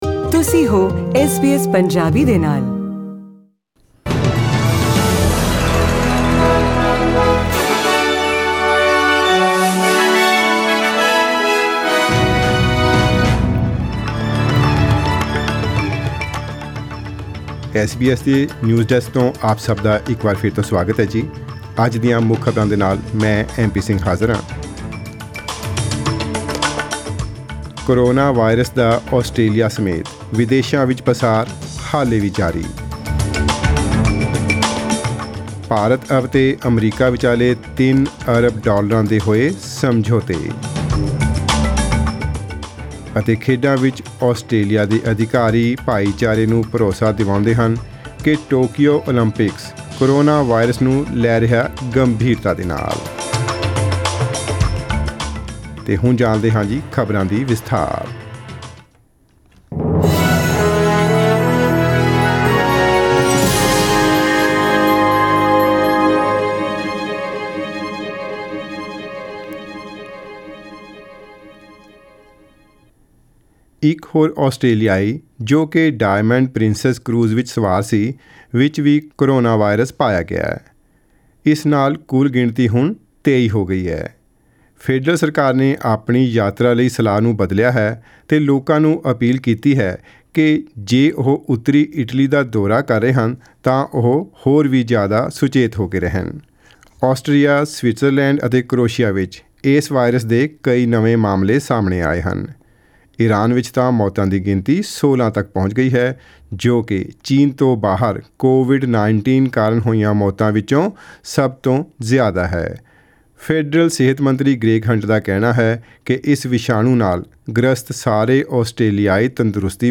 In today’s news bulletin:  Coronavirus continues its spread in Australia and overseas;  India and America sign pacts worth 3bn American dollars; and, In sport, Australian officials assure the community the Tokyo Olympics is taking the coronavirus threat seriously.